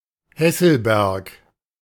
PronunciationGerman: [ˈhɛsəlbɛɐ̯k]
Hesselberg (pronounced [ˈhɛsl̩ˌbɛʁk]